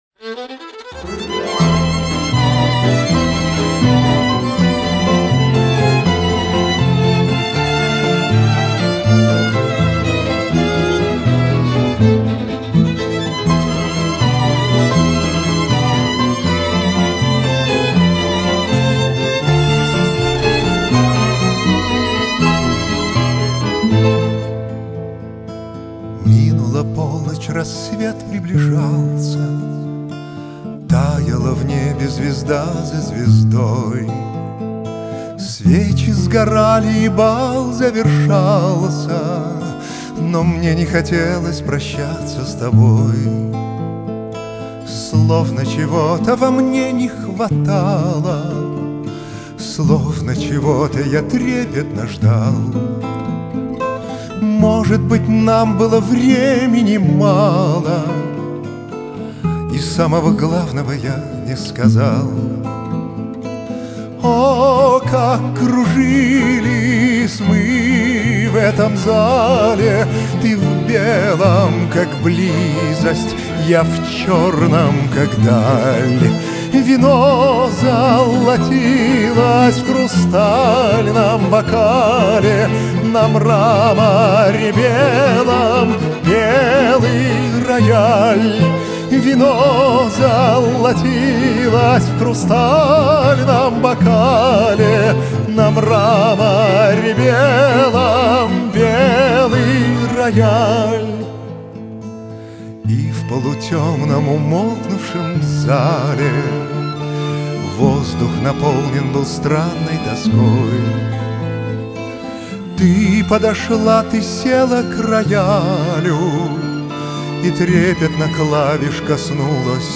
Красивые слова, Шикарный вокал.